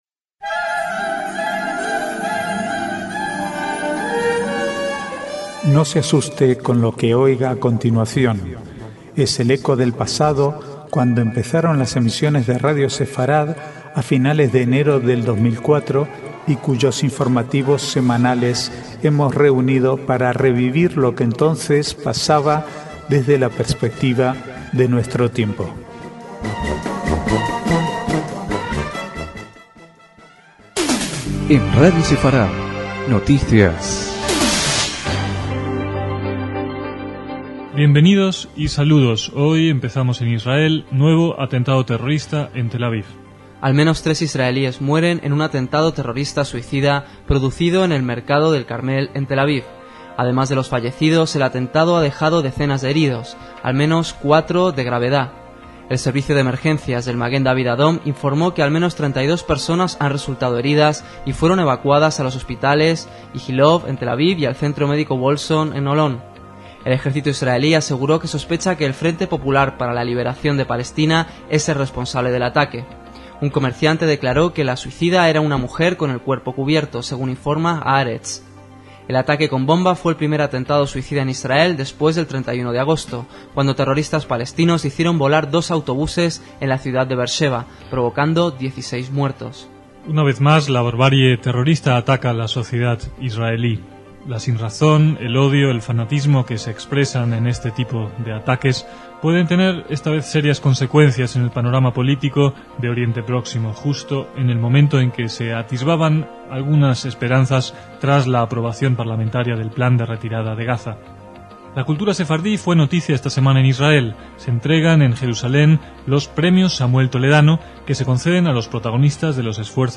informativos semanales